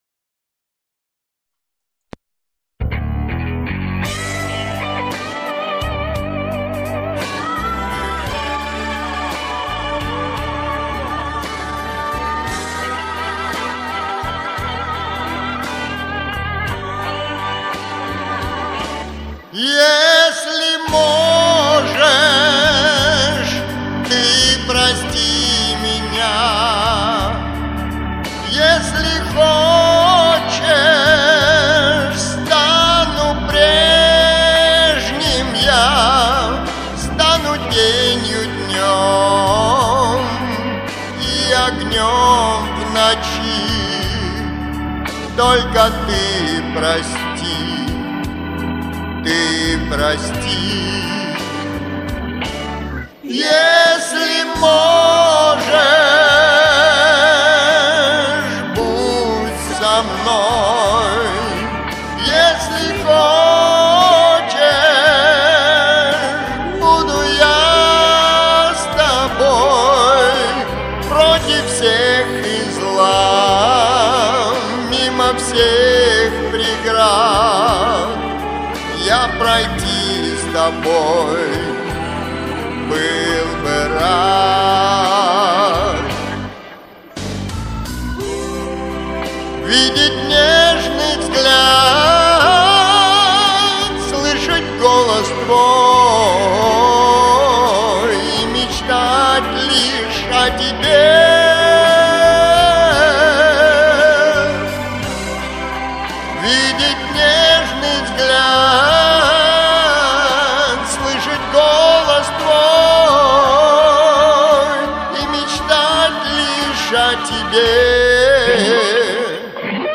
Оба такие певучие ) Сплошная кантилена.